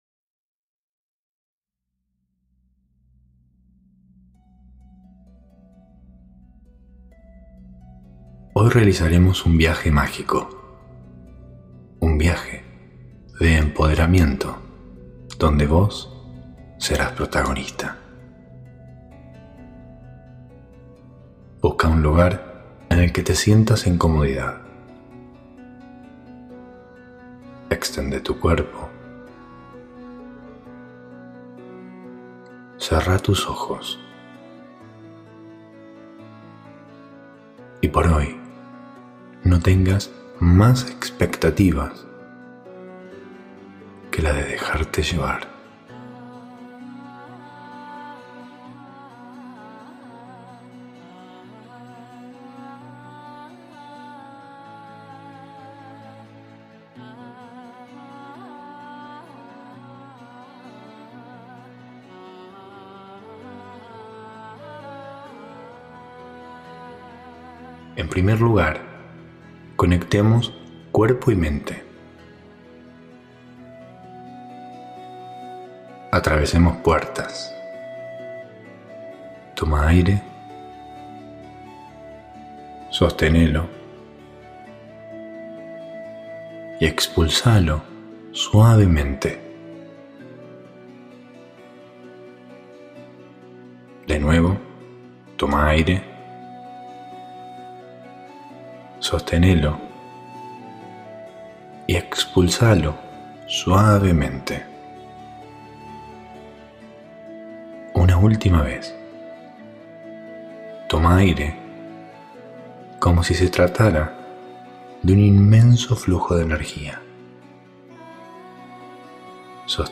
Esta meditación guiada, es una técnica para experimentar en primera persona un recorrido por tu propio castillo, adquiriendo en el camino grandes enseñanzas que te harán ganar una batalla interior. Una metáfora que te ayudará a entender lo valioso que es contar con la fuerza de tu propia valentía interna.